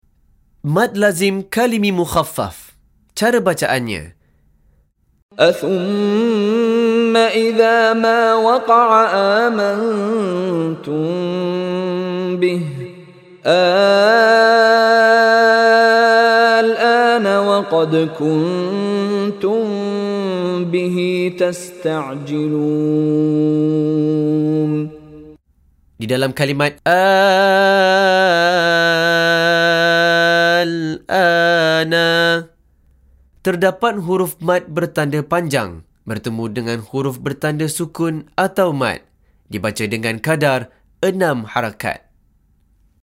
Penerangan Hukum + Contoh Bacaan dari Sheikh Mishary Rashid Al-Afasy
Dipanjangkan sebutan huruf Mad dengan 6 harakat sahaja.